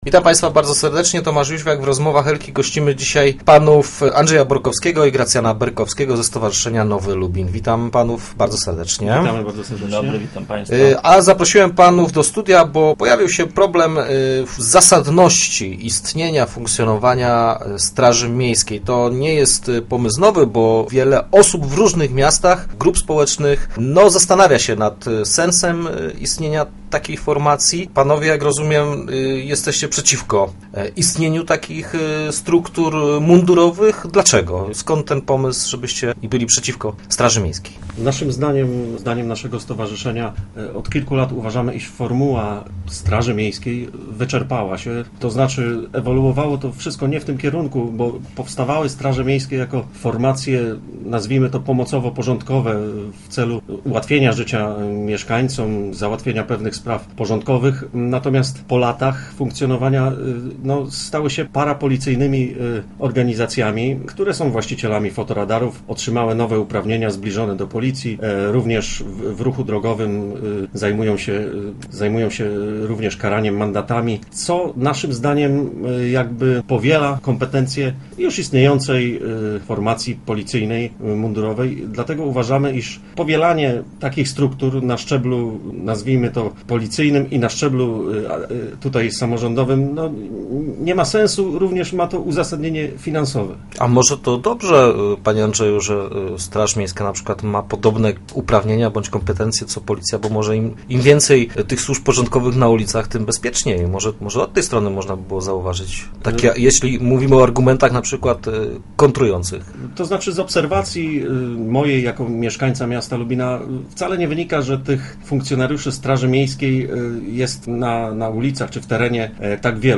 Mądry wywiad.